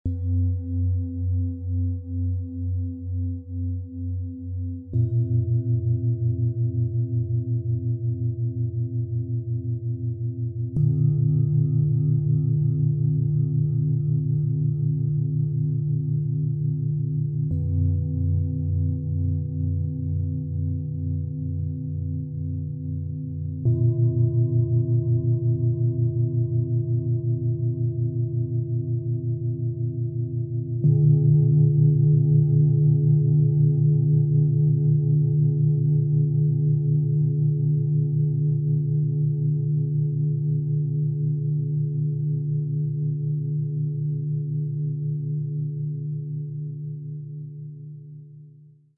Leicht berührt - Tief bewegt: Ruhe, Ankommen und innere Harmonie - Klangmassage-Set aus 3 Klangschalen, Ø 16,9 - 22,5 cm, 2,54 kg
Tiefster Ton – erdend, beruhigend, stärkend
Mittlerer Ton – öffnend, harmonisierend, freudvoll
Höchster Ton – fokussierend, klärend, gelassen
Im Sound-Player - Jetzt reinhören können Sie den Original-Ton genau dieser Schalen, des Sets anhören.
Der inkludierte Klöppel ist ideal auf die Schalen abgestimmt und erzeugt einen warmen, angenehmen Klang.
Bengalen Schale, Matt, 22,5 cm Durchmesser, 9,5 cm Höhe
MaterialBronze